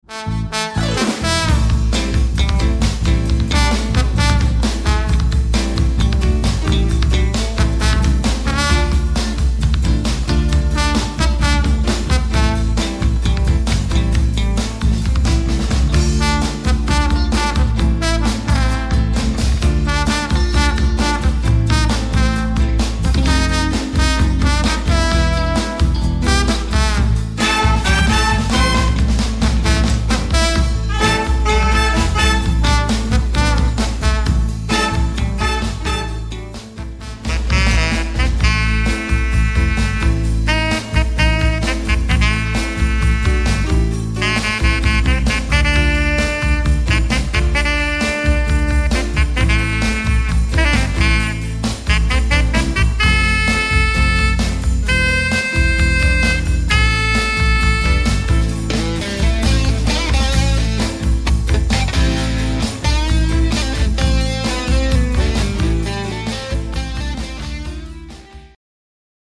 Street Band